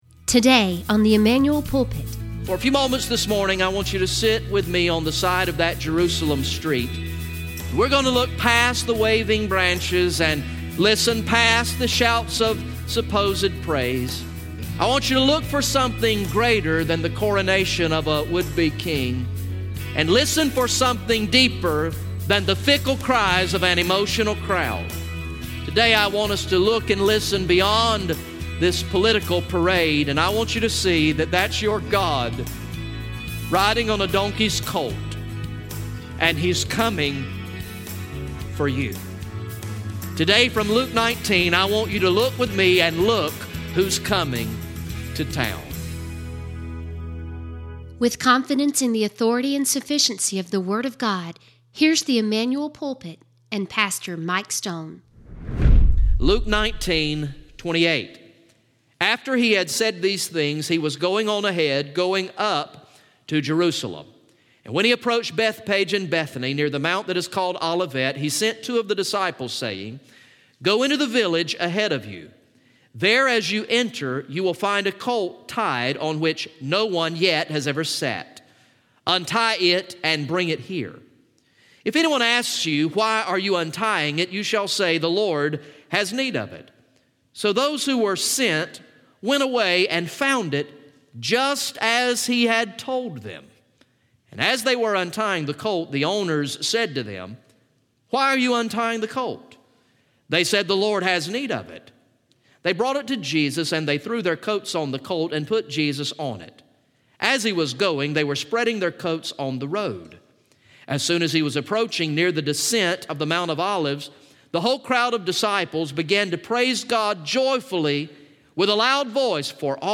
From the morning worship service on Sunday, March 25, 2018